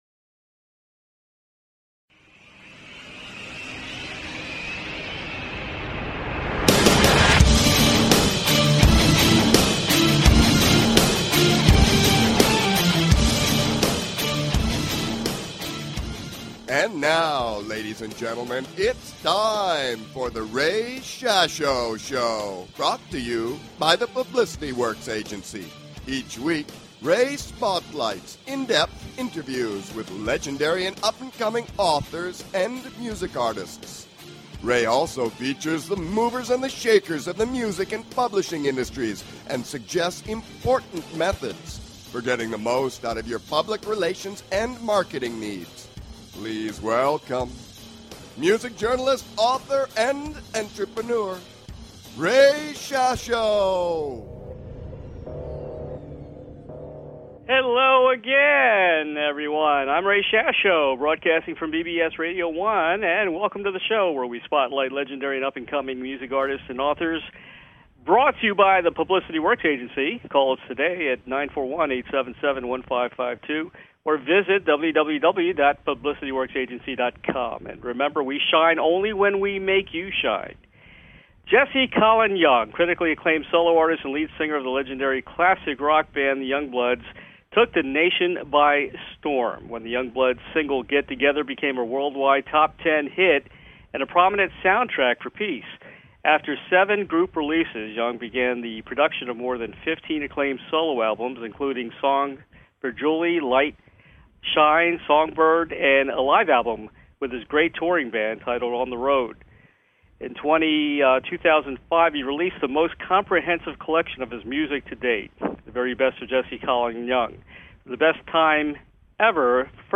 Talk Show Episode
Guest, Jesse Colin Young